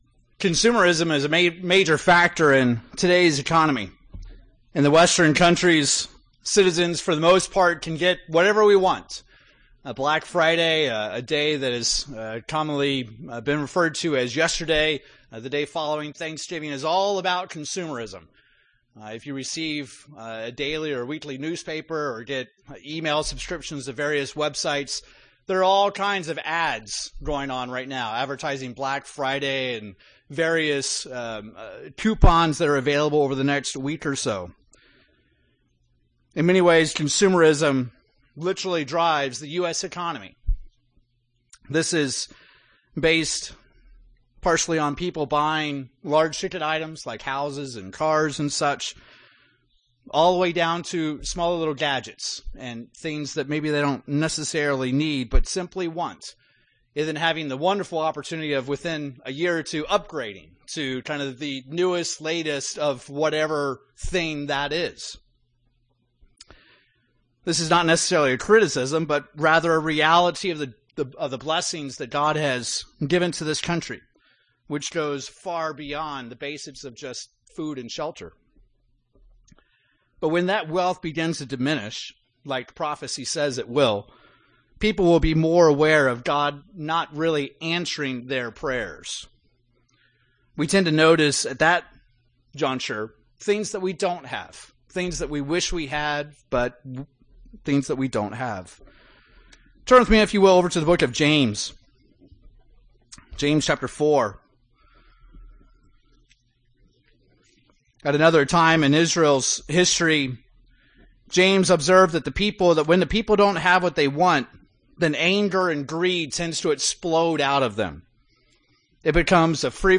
UCG Sermon Notes Consumerism is a major factor in today's economy.